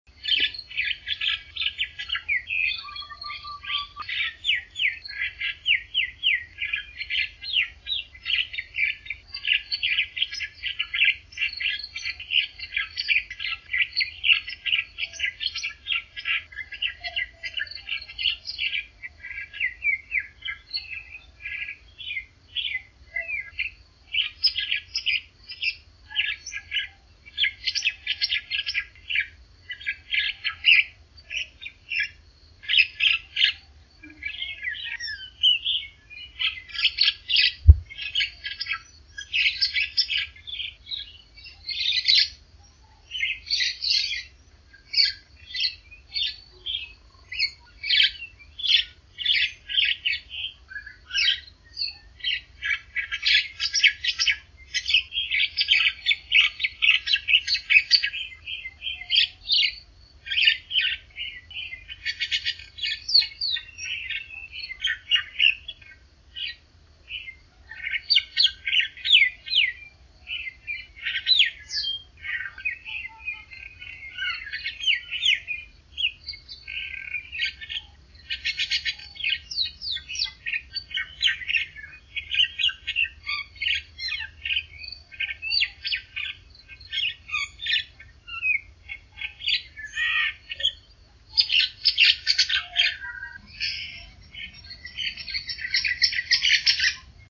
Martín Pescador Común (Alcedo atthis)